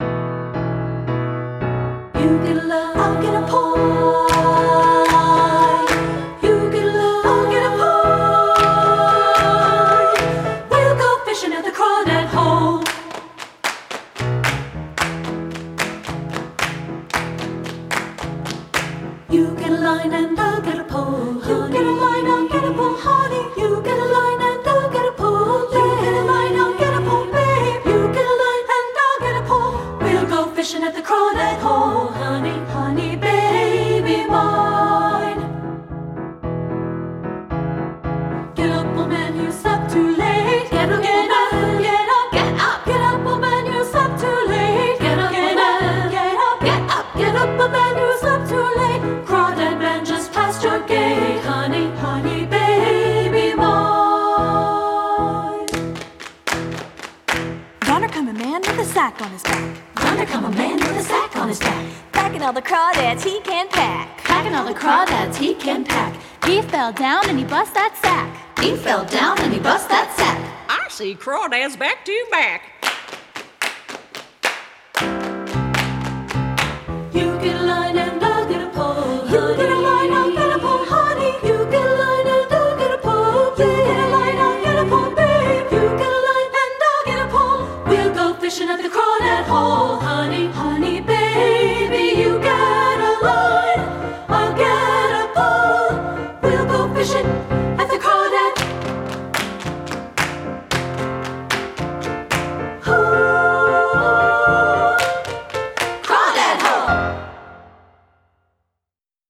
Two-Part Treble Voices with Piano
• Piano
Studio Recording
rhythmic, playful arrangement
Ensemble: Treble Chorus
Key: E major
Tempo: Bright and fun! (q = 120)
Accompanied: Accompanied Chorus